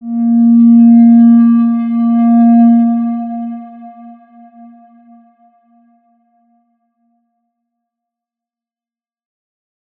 X_Windwistle-A#2-pp.wav